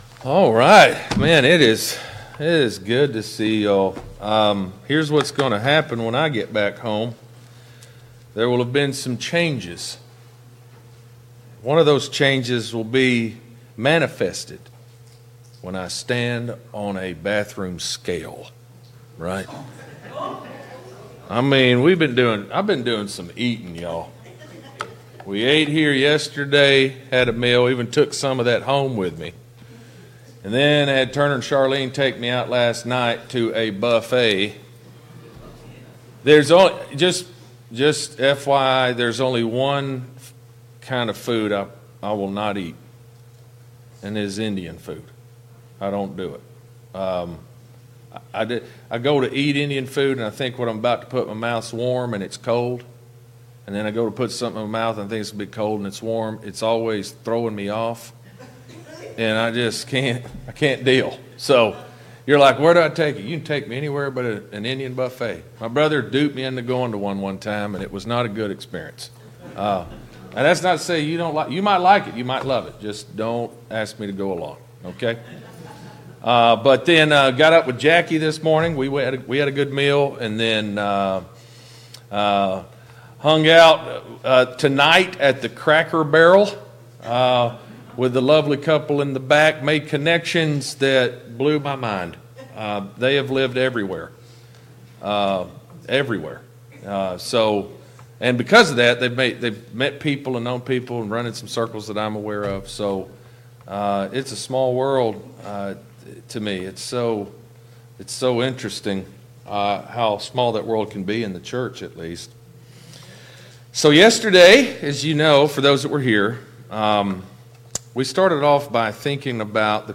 Service Type: Gospel Meeting Download Files Notes Topics: The Resurrection of Jesus Christ « 3.